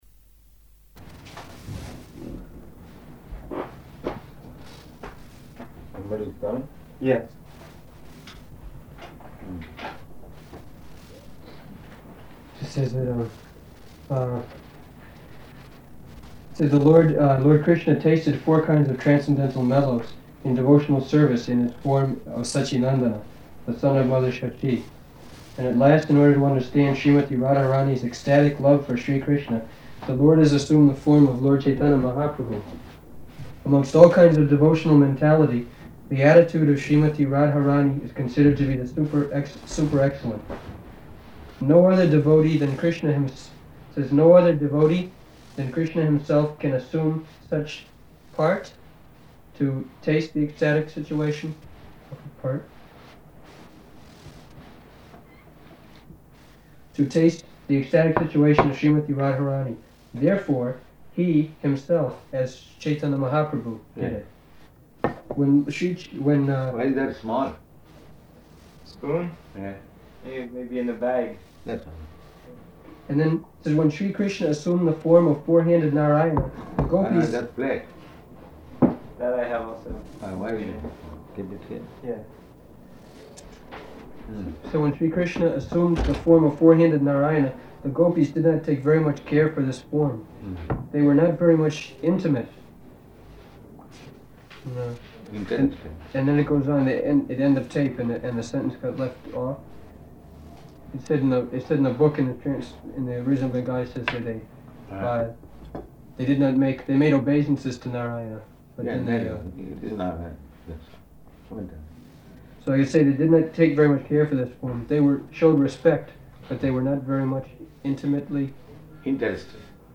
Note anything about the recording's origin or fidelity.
-- Type: Conversation Dated: September 10th 1973 Location: Stockholm Audio file